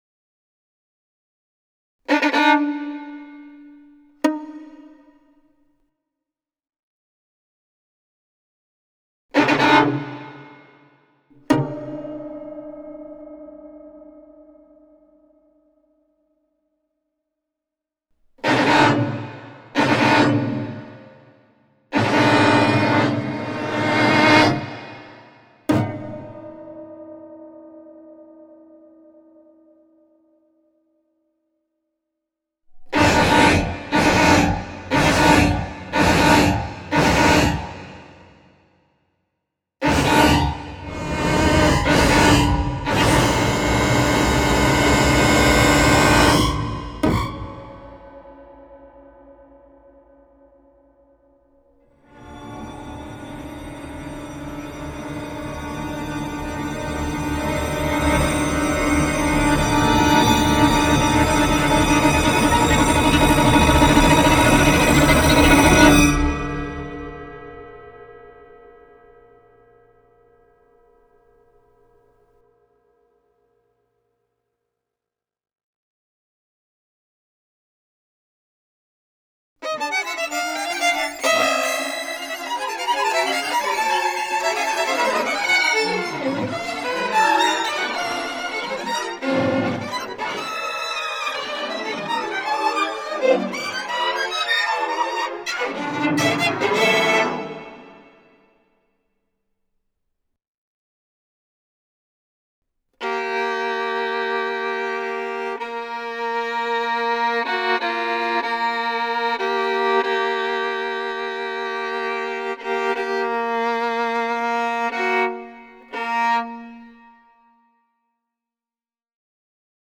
violin Score